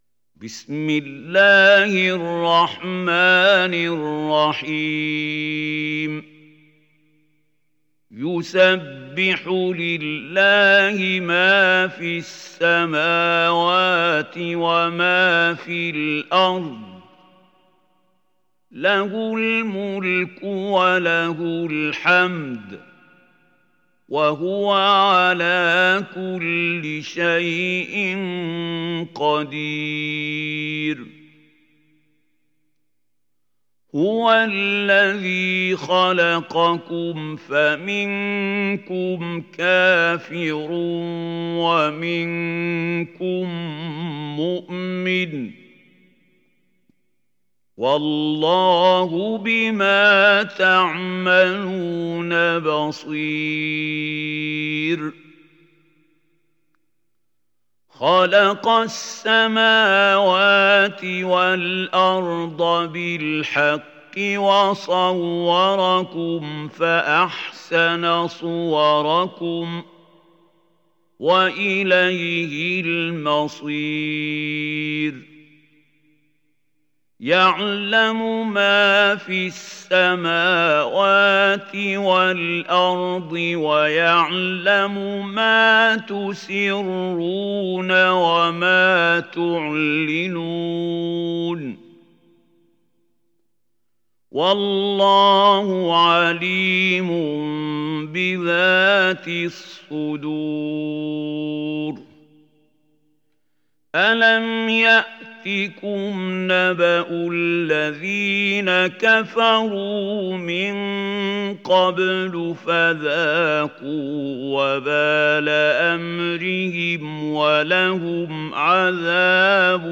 تحميل سورة التغابن mp3 بصوت محمود خليل الحصري برواية حفص عن عاصم, تحميل استماع القرآن الكريم على الجوال mp3 كاملا بروابط مباشرة وسريعة